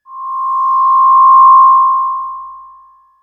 BadTransmission5.wav